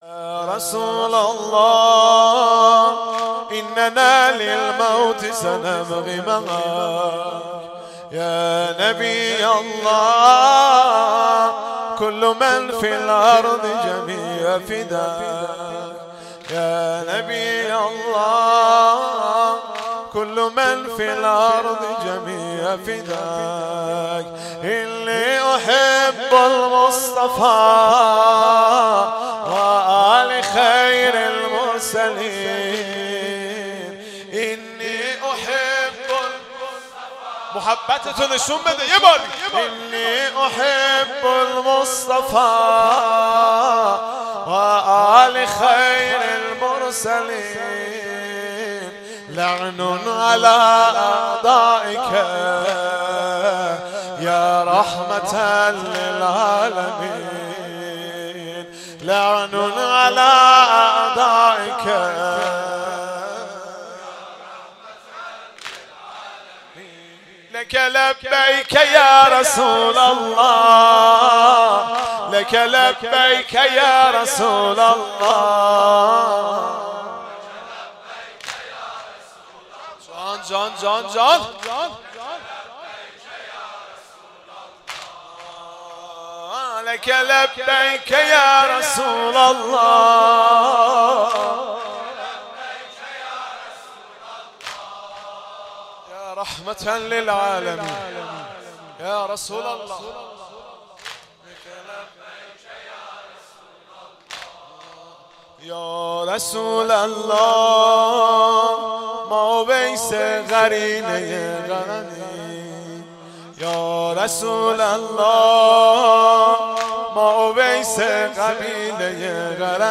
عقیق: مراسم هفتگی هیئت ثارالله زنجان، پنجشنبه 2/11/1393 در گلزار شهدای حضرت فاطمه (س) این شهر برگزار شد. در این مراسم حاج مهدی رسولی به مداحی در پاسخ به توهین اخیر به ساحت نبی گرامی اسلام پرداخت.